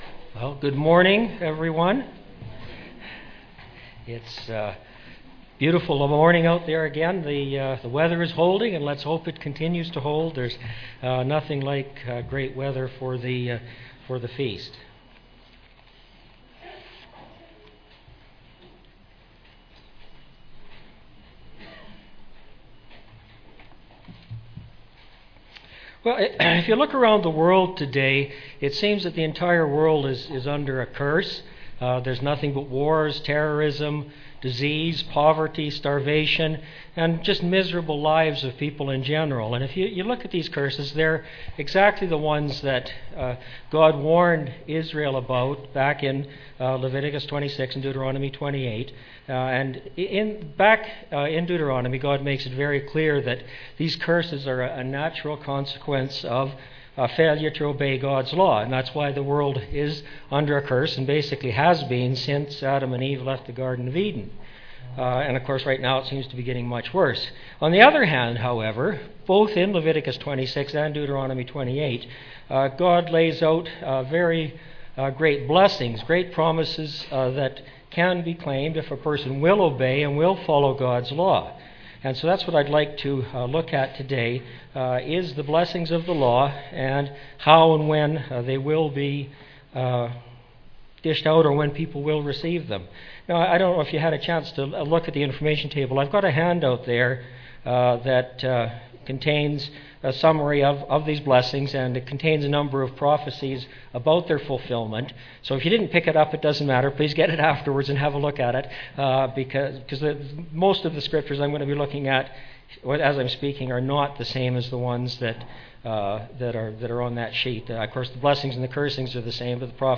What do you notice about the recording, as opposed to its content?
This sermon was given at the Canmore, Alberta 2014 Feast site.